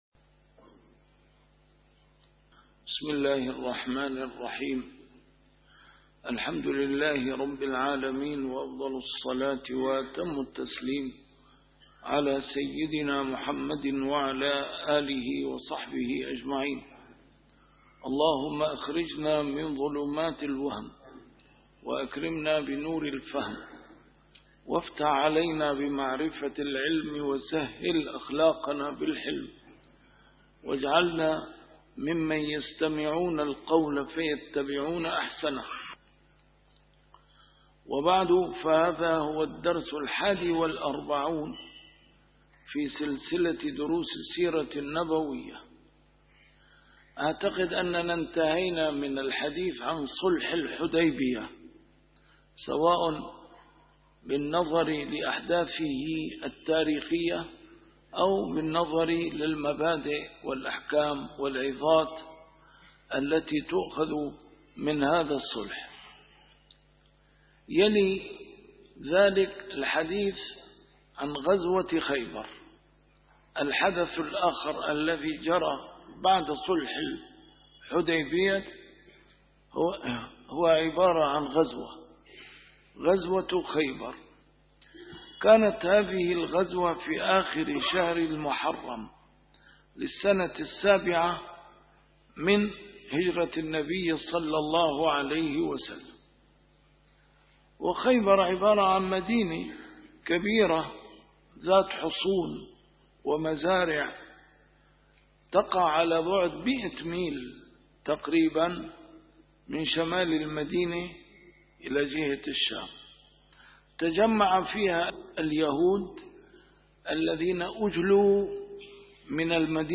A MARTYR SCHOLAR: IMAM MUHAMMAD SAEED RAMADAN AL-BOUTI - الدروس العلمية - فقه السيرة النبوية - فقه السيرة / الدرس الواحد والأربعون : غزوة خيبر (1)